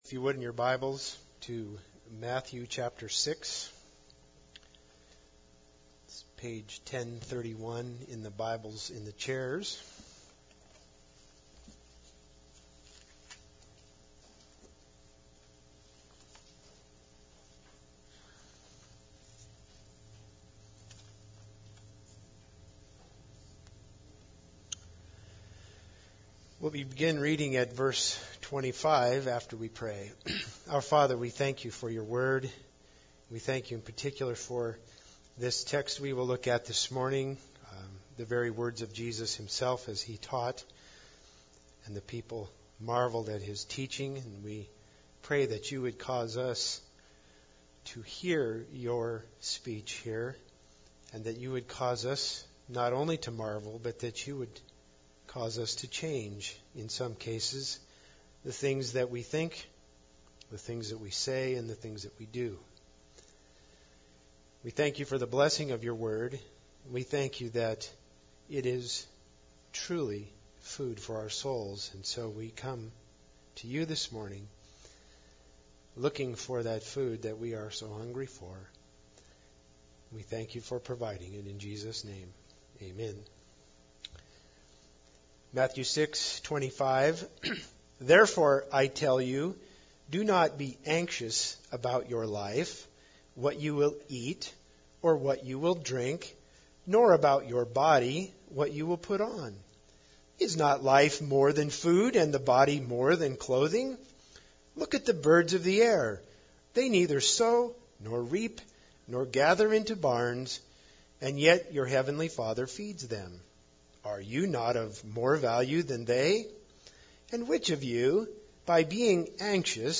Matthew 6:25-34 Service Type: Sunday Service Bible Text